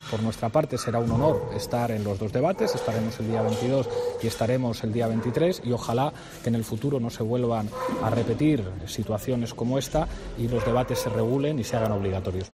Pablo Iglesias ha expuesto esta opinión en declaraciones a los periodistas, en una comparecencia sin preguntas, después de que el presidente del Gobierno, Pedro Sánchez, haya aceptado participar en los dos debates -el día 22 en RTVE y el 23 en Atresmedia- con los candidatos del PP, Cs y Unidas Podemos.